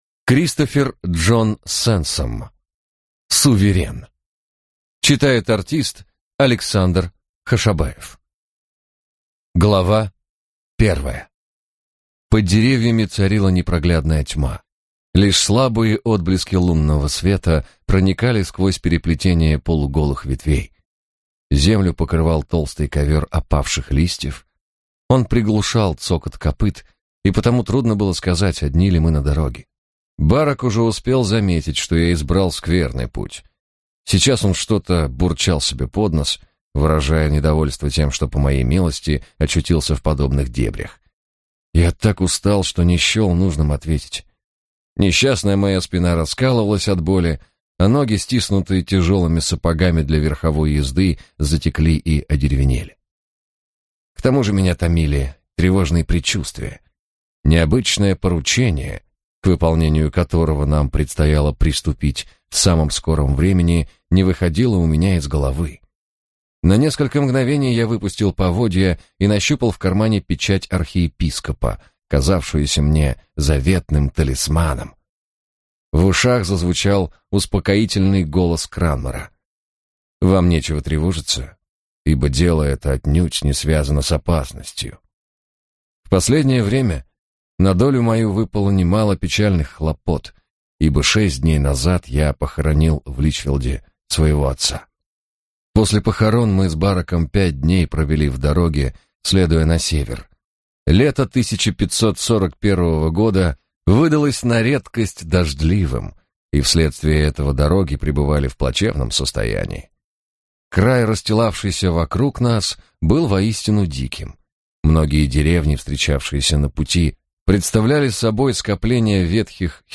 Аудиокнига Суверен | Библиотека аудиокниг